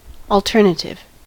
alternative: Wikimedia Commons US English Pronunciations
En-us-alternative.WAV